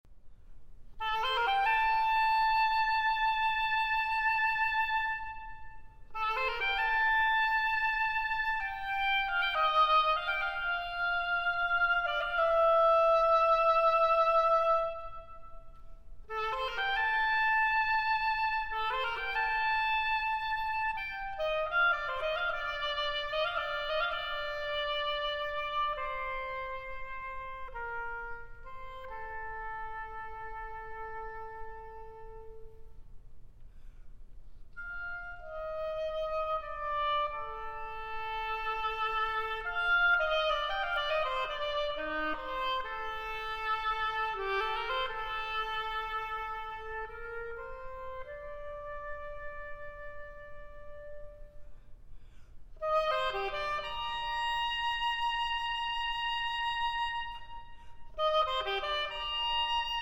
Oboe
Piano